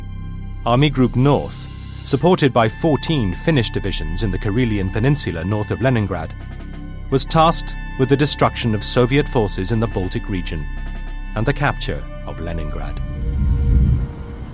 The speaker just says /tɑːst/ and if you don’t know about the elision of the /k/ you might have trouble understanding him.
was-tasked-British-documentary.mp3